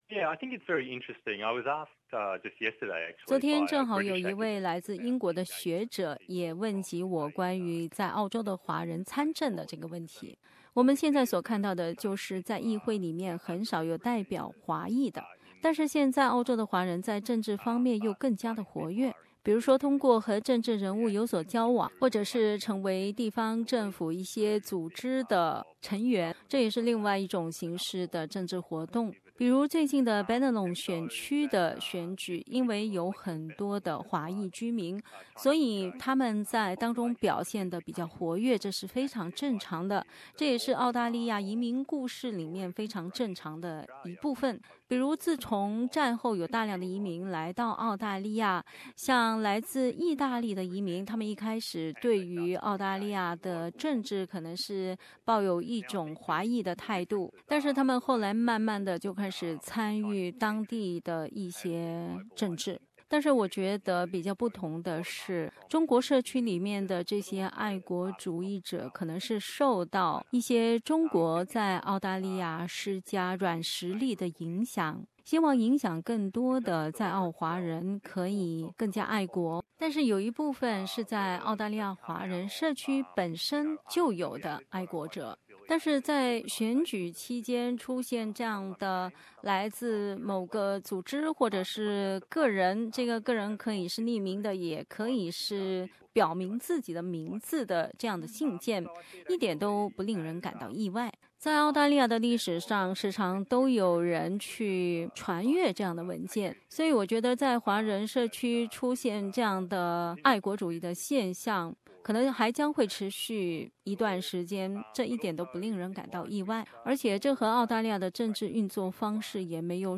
（本节目为嘉宾观点，不代表本台立场) READ MORE 让Bennelong自由党下台！